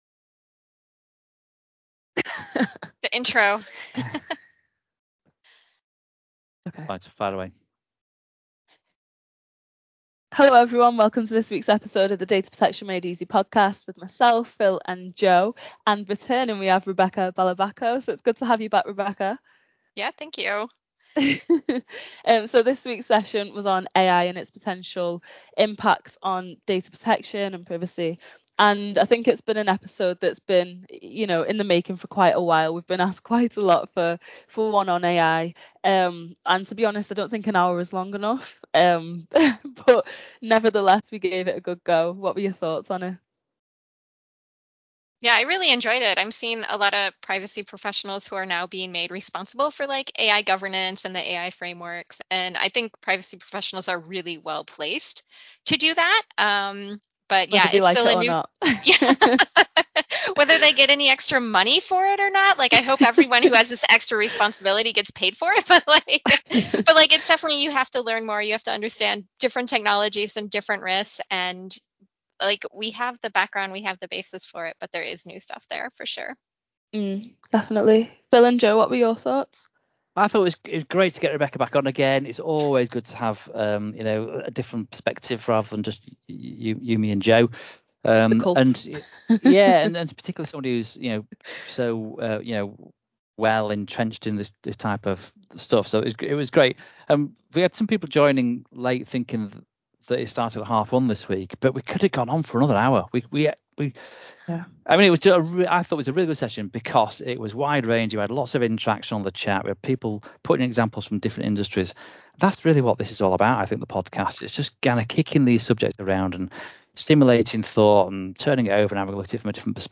joins our expert hosts for a thought-provoking discussion.